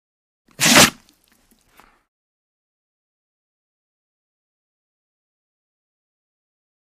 Dog, German Shepherd Sneeze, Quick. Close Perspective.